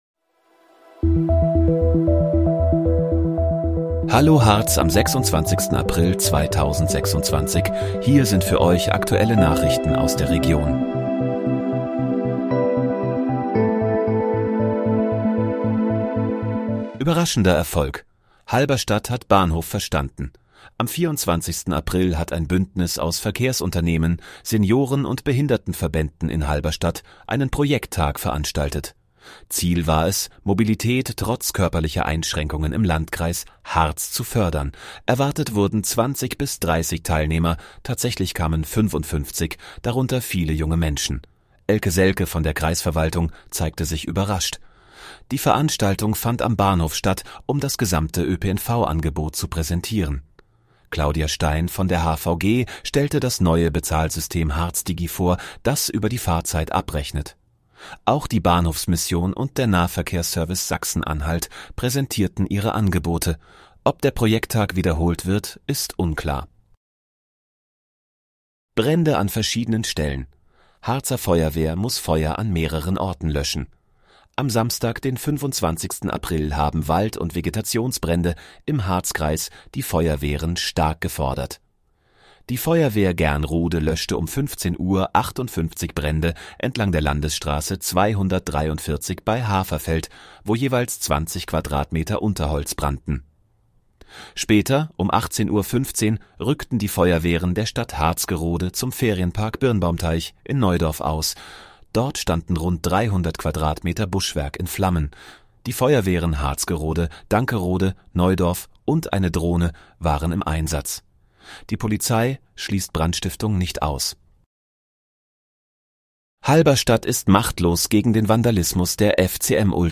Hallo, Harz: Aktuelle Nachrichten vom 26.04.2026, erstellt mit KI-Unterstützung